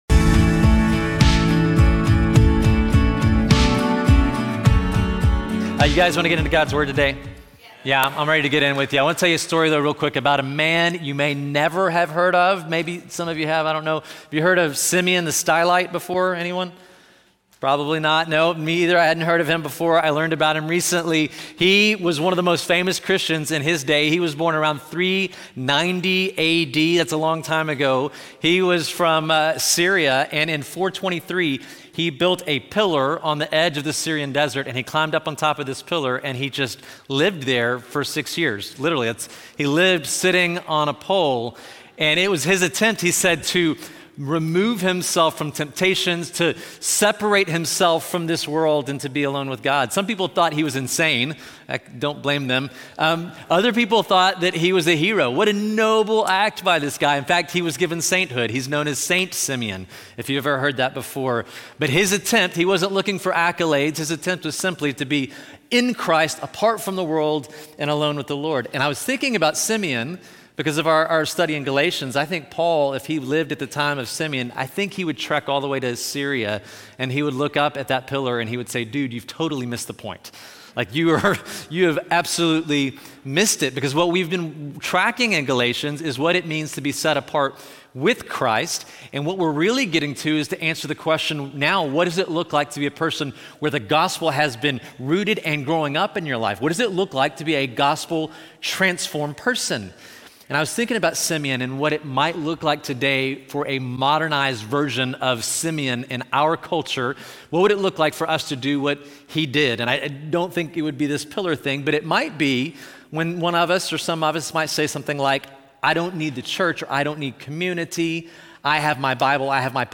This sermon from Galatians 6 challenges believers not to isolate themselves in personal spirituality, but to live out the gospel by engaging humbly and sacrificially in the lives of others through Spirit-led action.